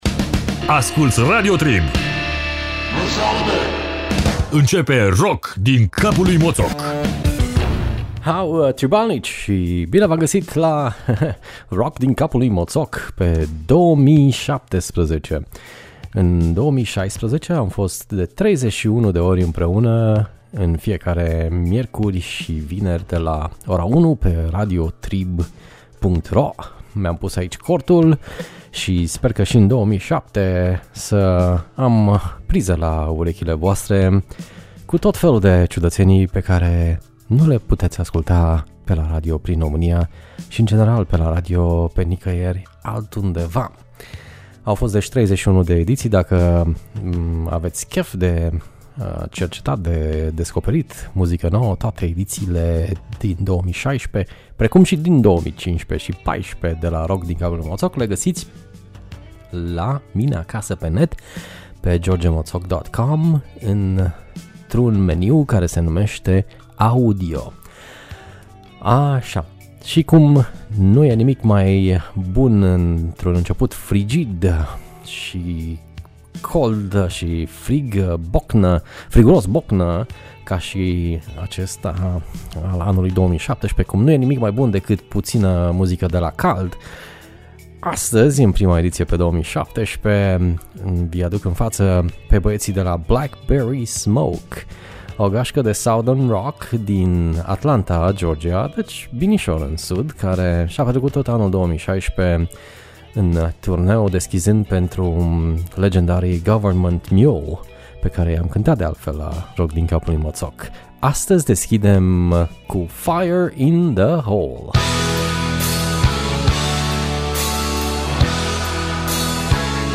Incepem 2017 cu Southern Rock, ca sarea in bucate.